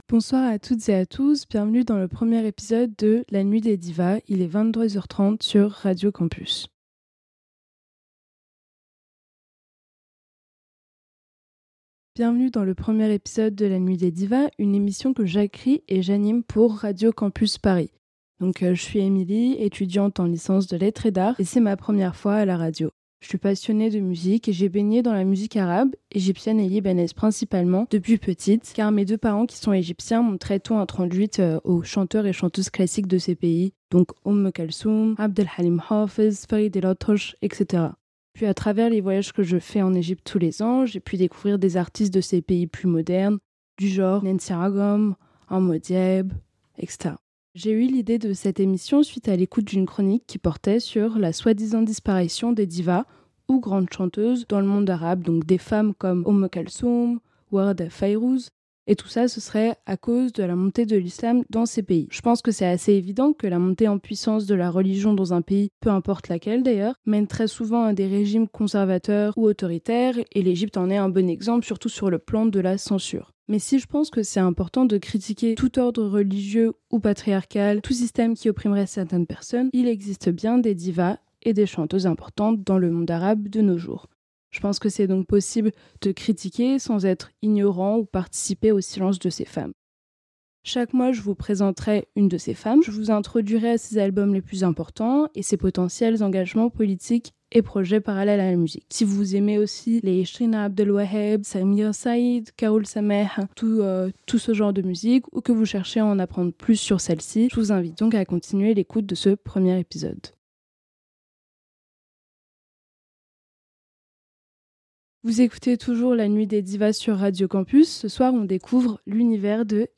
Cette chanteuse est apparue à la fin des années 90 et a petit à petit construit une carrière solide caractérisée par son style très dramatique et pop.